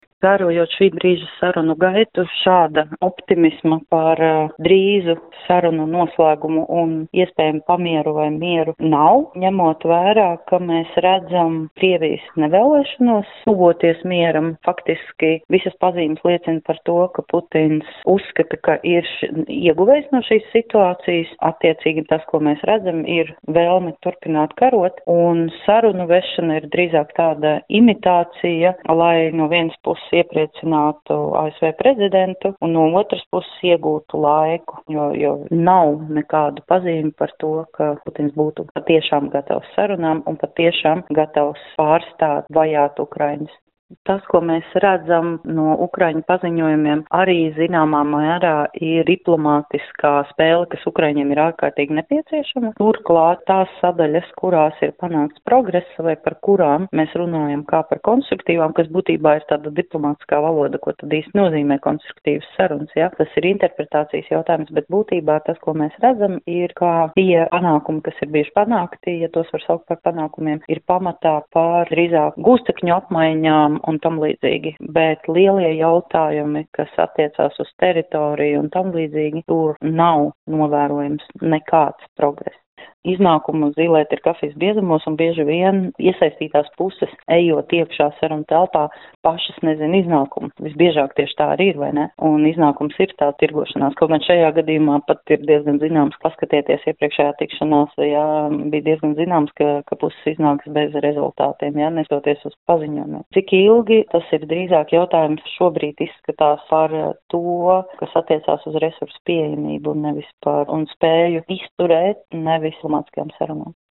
intervijā Skonto mediju grupai